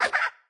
Media:Chicken_baby_atk_6.wav 攻击音效 atk 初级形态攻击音效
Chicken_baby_atk_6.wav